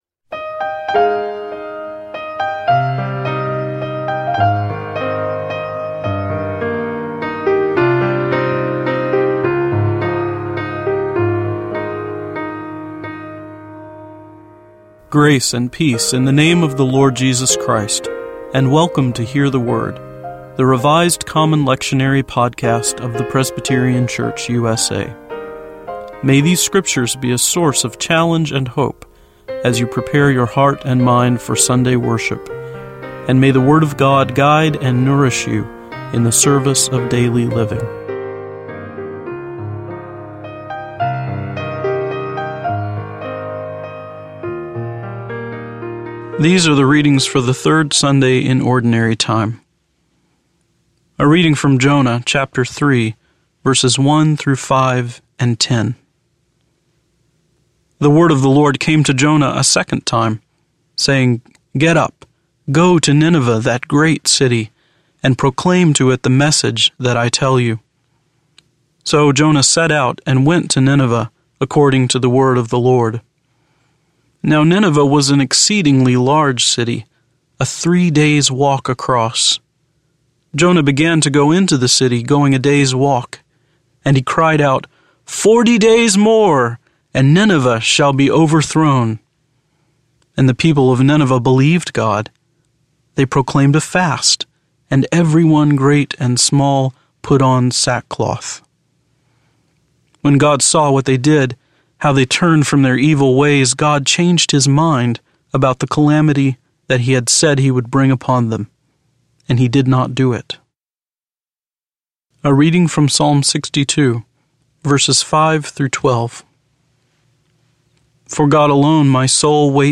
Each podcast (MP3 file) includes four lectionary readings for one of the Sundays or festivals of the church year: an Old Testament reading, a Psalm, an Epistle and a Gospel reading. Following each set of readings is a prayer for the day from the Book of Common Worship.